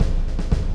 Drum1.wav